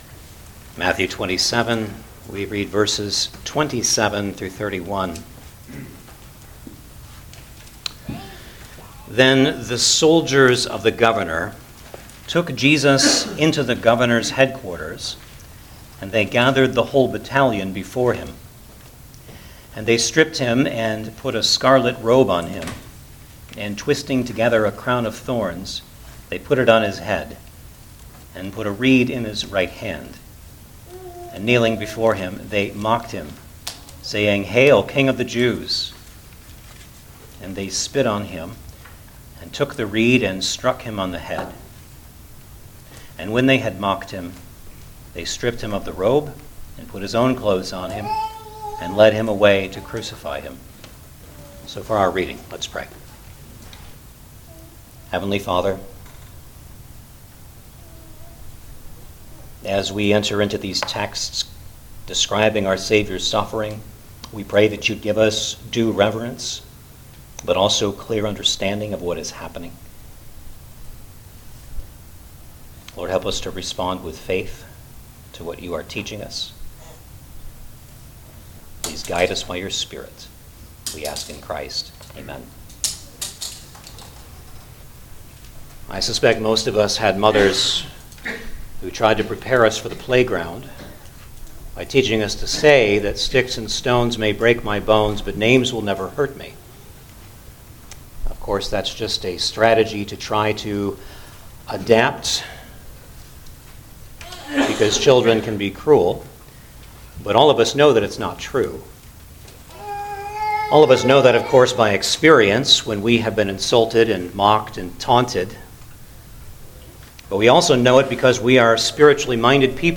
Matthew 27:27-31 Service Type: Sunday Morning Service Download the order of worship here .